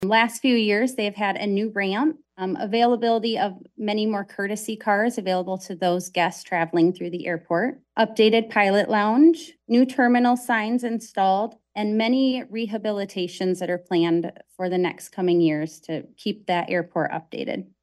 COLDWATER, MI (WTVB) – A representative from the Michigan Department of Transportation Aviation Unit told Branch County Commissioners during their work session last Thursday that the Branch County Memorial Airport is one of the highest ranked airports in the State of Michigan.